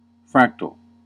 Ääntäminen
UK : IPA : /ˈfɹæk.təl/ US : IPA : /ˈfɹæk.təl/